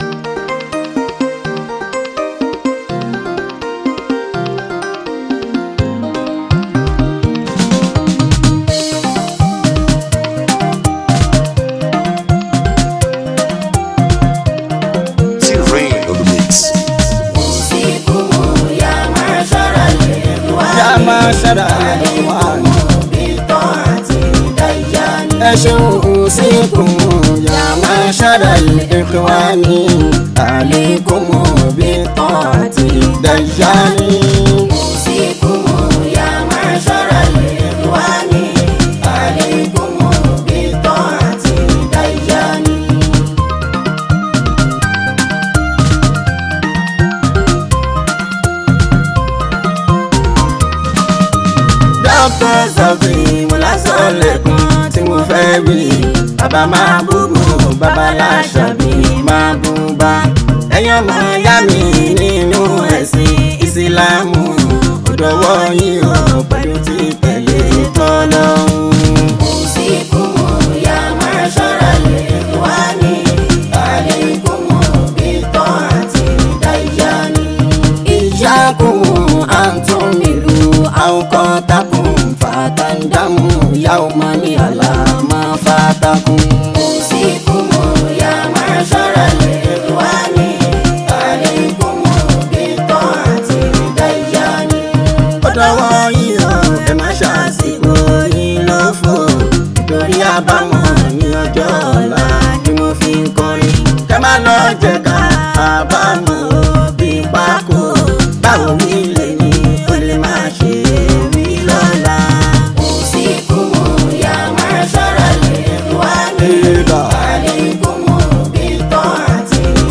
Yoruba Fuji Hit song
Nigerian Yoruba Fuji track
be ready to dance to the beats